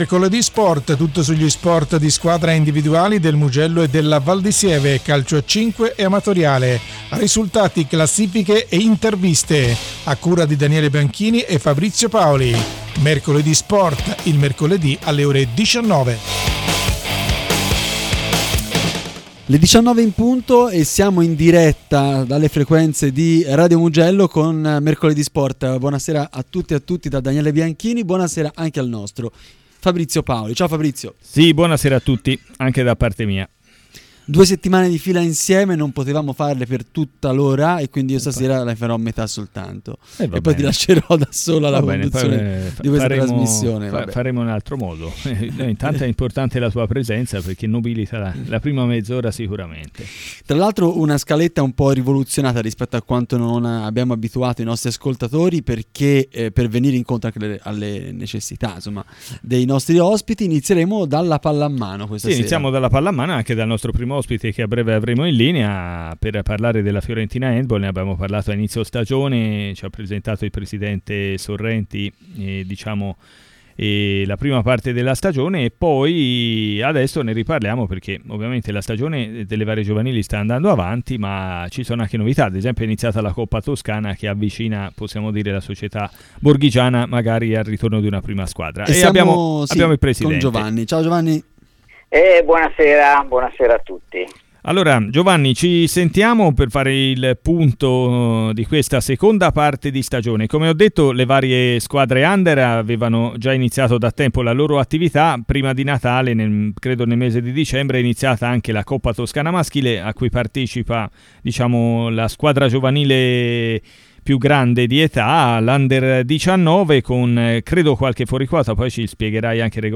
A seguire risultati e classifiche di volley. Nella seconda parte spazio invece alla consueta pagina del basket con i risultati delle squadre del Mugello e Valdisieve. Nella terza parte si parla di ciclismo alla vigilia della ripresa dell’attività su strada con un’intervista